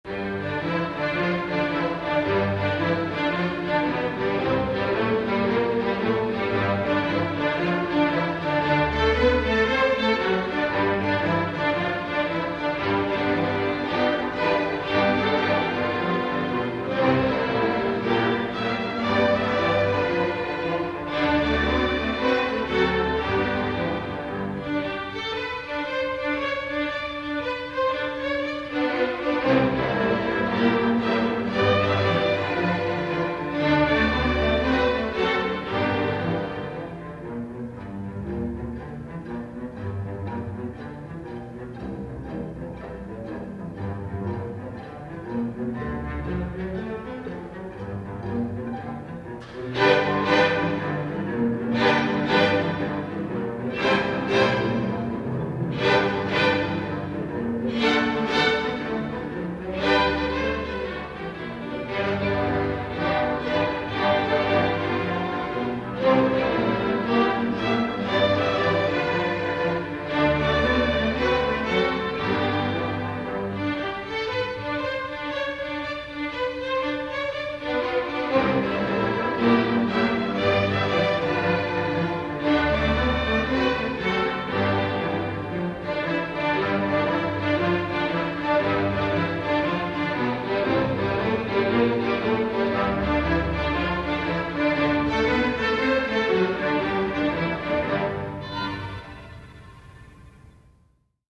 Une pièce dans un style jazz.